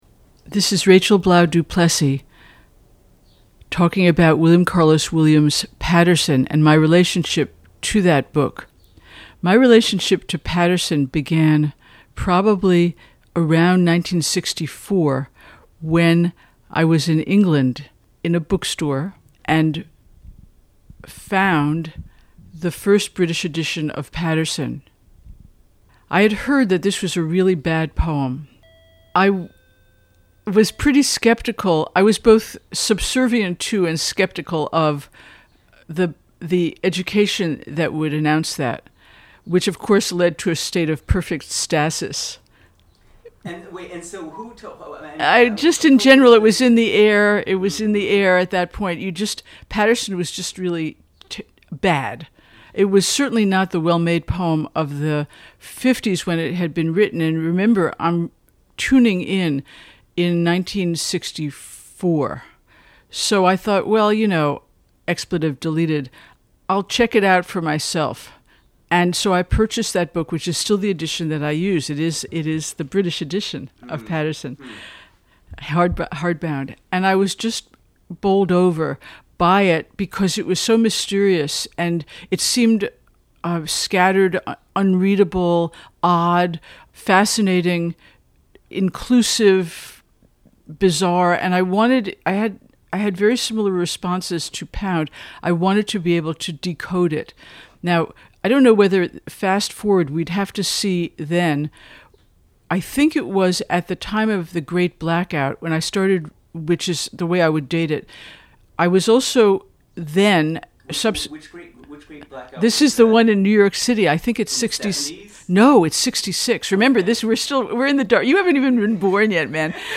It has always been a question for me whether any consolidation is worthwhile or advisable, which is why interviews like those below have been kept in as close to their rawest form (with some mild editing) because I have felt it is better to make them available during the process, rather than to wait and wait for an ideal form that never comes.